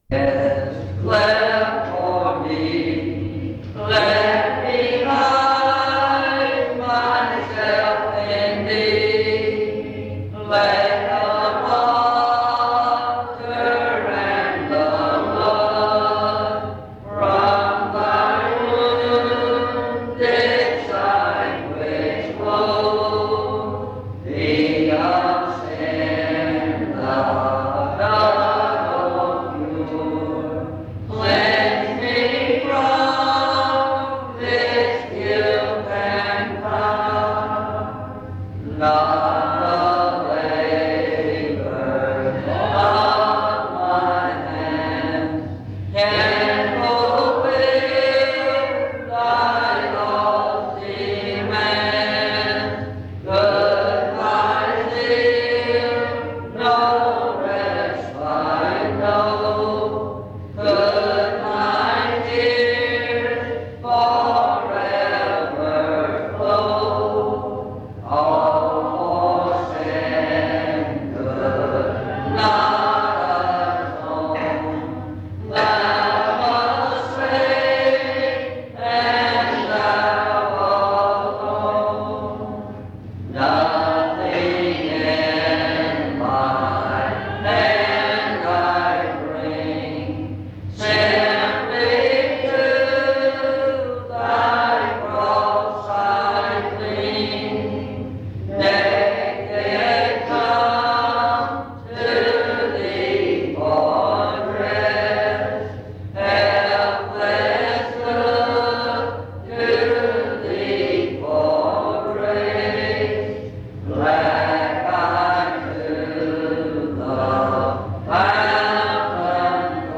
In Collection: Reidsville/Lindsey Street Primitive Baptist Church audio recordings Thumbnail Titolo Data caricata Visibilità Azioni PBHLA-ACC.001_031-B-01.wav 2026-02-12 Scaricare PBHLA-ACC.001_031-A-01.wav 2026-02-12 Scaricare